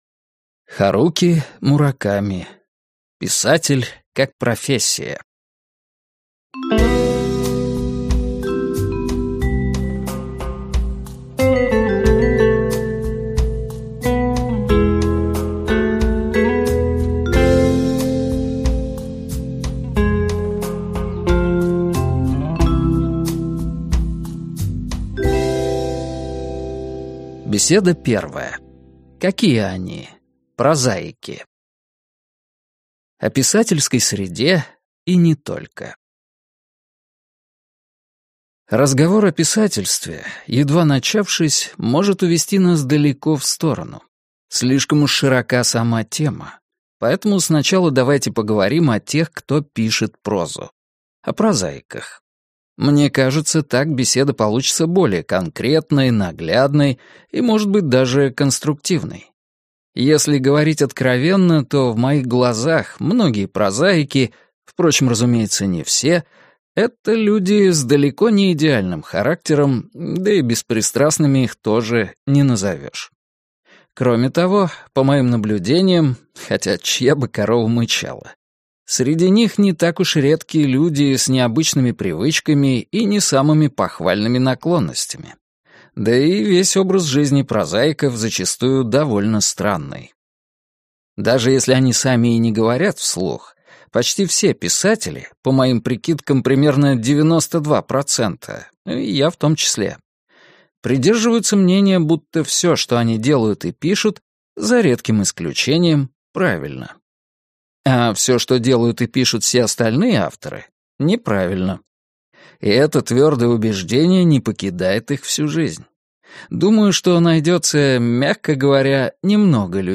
Аудиокнига Писатель как профессия - купить, скачать и слушать онлайн | КнигоПоиск
Аудиокнига «Писатель как профессия» в интернет-магазине КнигоПоиск ✅ в аудиоформате ✅ Скачать Писатель как профессия в mp3 или слушать онлайн